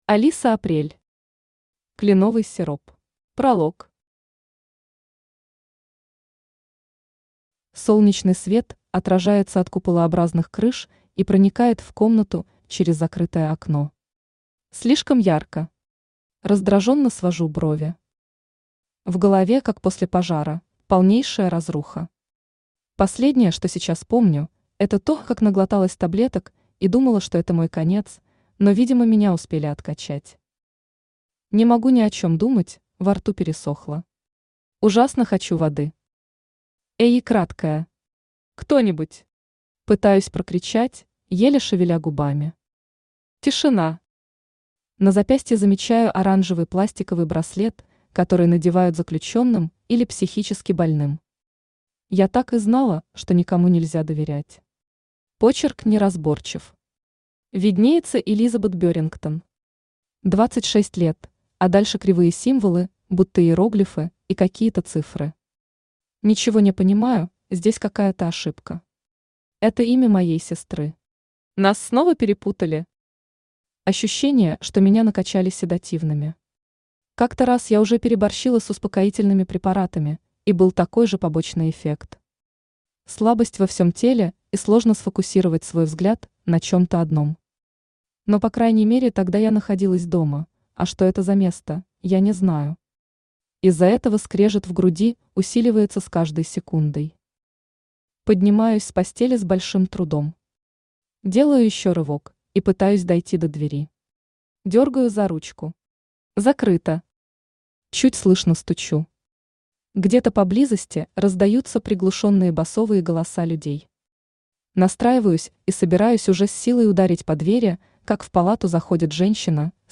Аудиокнига Кленовый сироп | Библиотека аудиокниг
Aудиокнига Кленовый сироп Автор Алиса Апрель Читает аудиокнигу Авточтец ЛитРес.